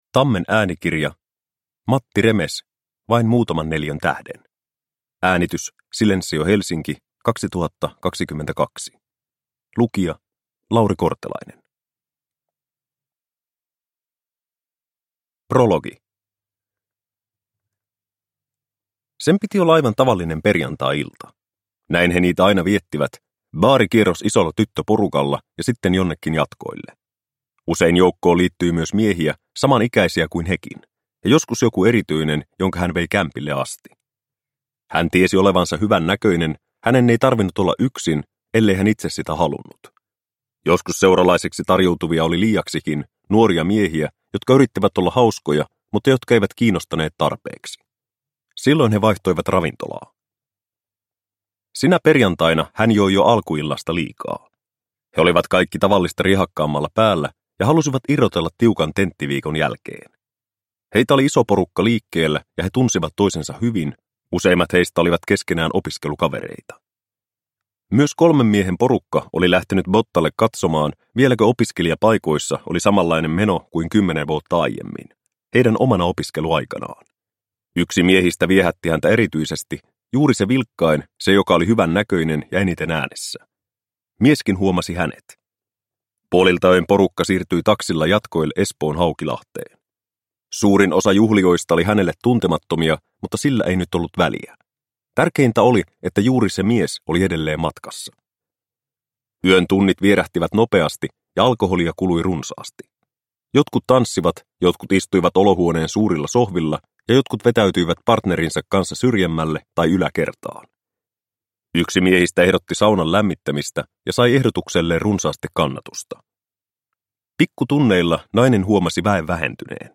Vain muutaman neliön tähden – Ljudbok – Laddas ner